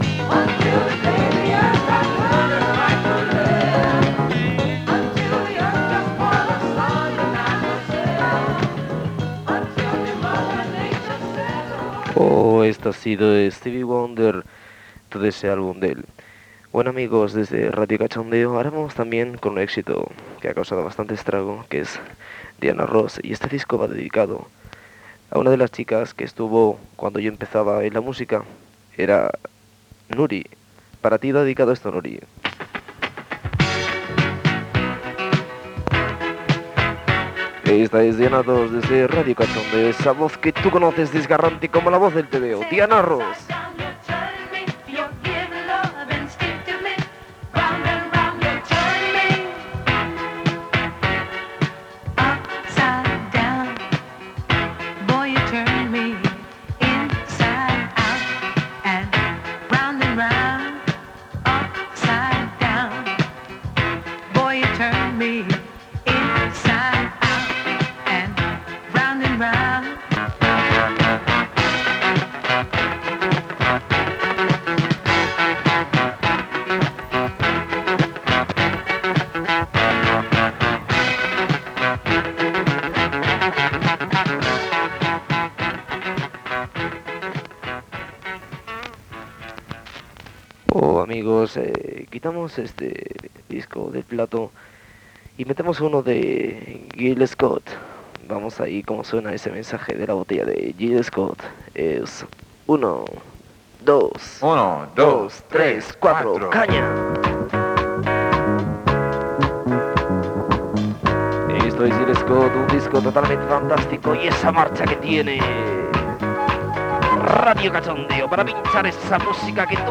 ee39a76b94c6a988ed60f17576b73289b4e22f47.mp3 Títol Radio Cachondeo Emissora Radio Cachondeo Titularitat Tercer sector Tercer sector Musical Descripció Identificacions i temes musicals.